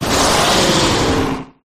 arctovish_ambient.ogg